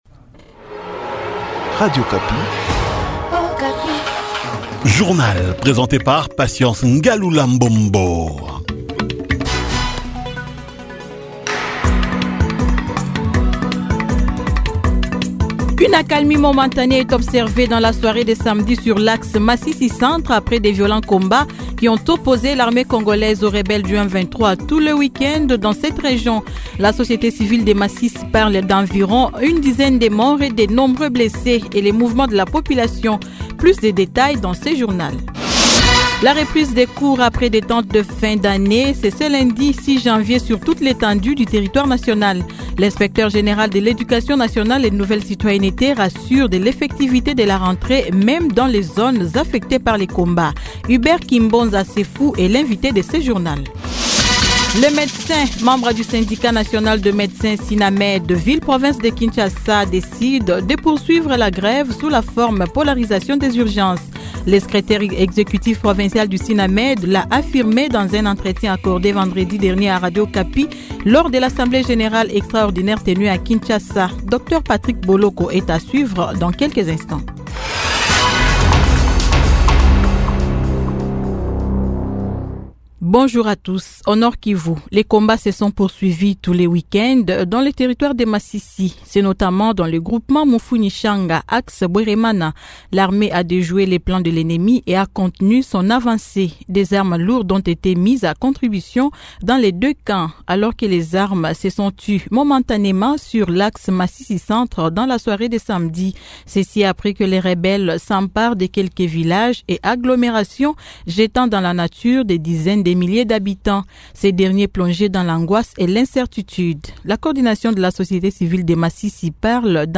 Journal matin 06H-07H